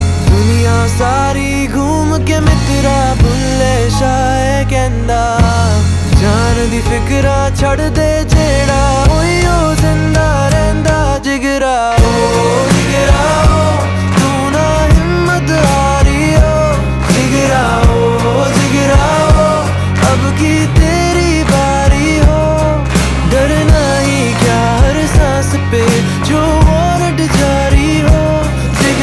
A Melodious Fusion
• Simple and Lofi sound
• Crisp and clear sound